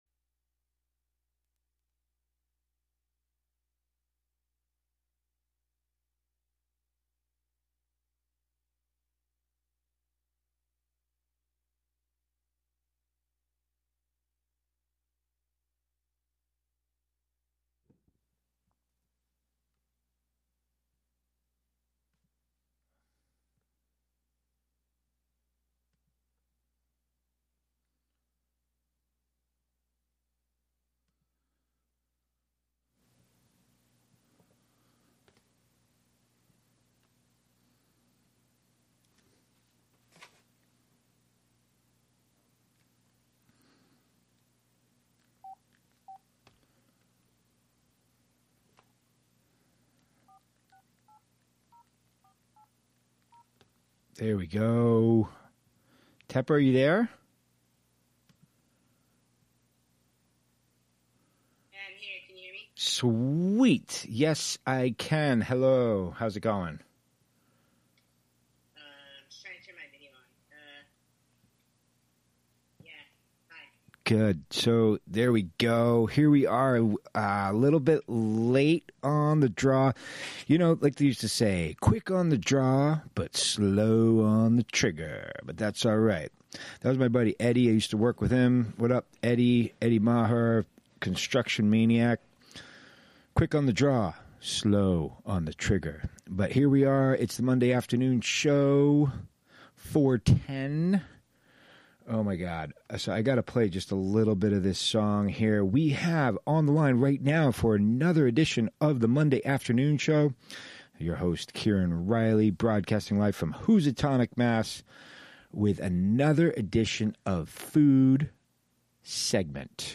broadcasts live with music, call-ins, news, announcements, and interviews